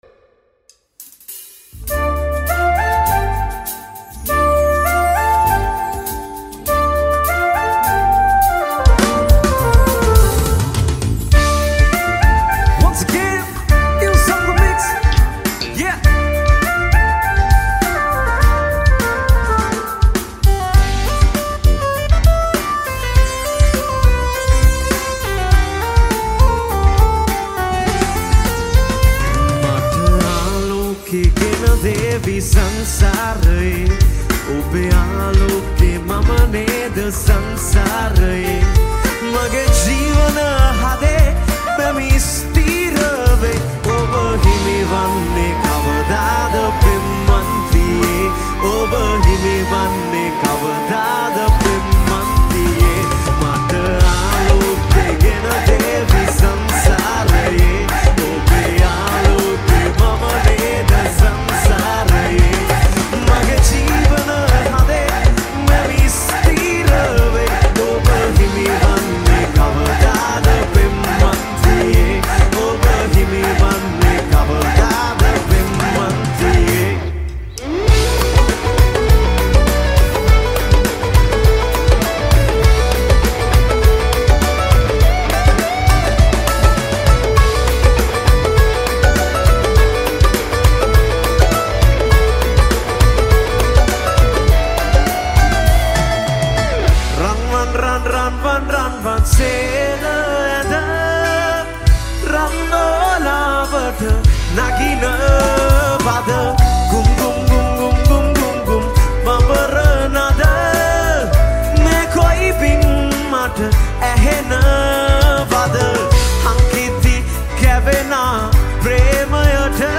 High quality Sri Lankan remix MP3 (5.7).